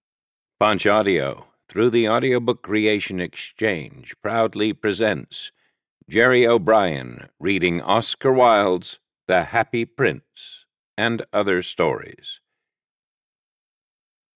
The Happy Prince and Other Tales (EN) audiokniha
Ukázka z knihy